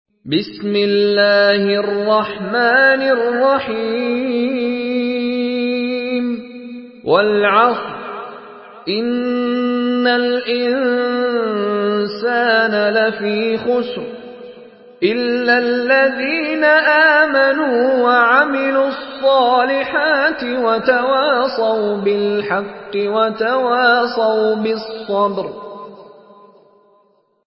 Surah العصر MP3 by مشاري راشد العفاسي in حفص عن عاصم narration.